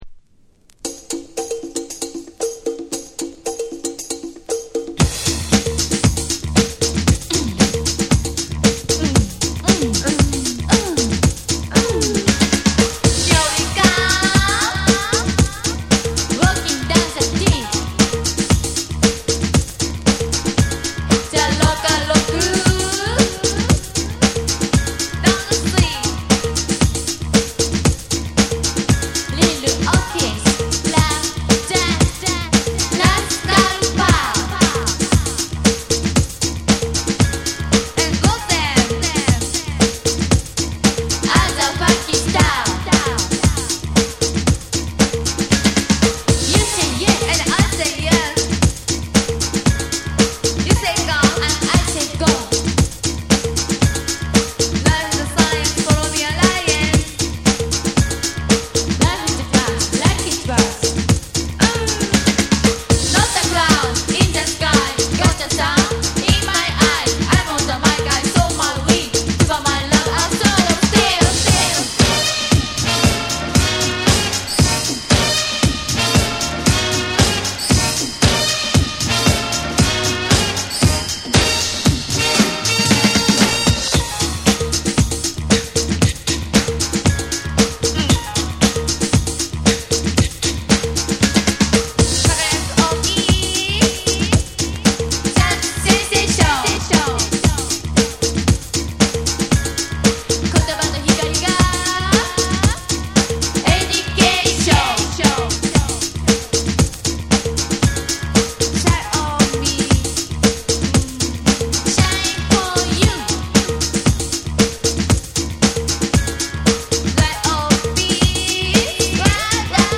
JAPANESE / BREAKBEATS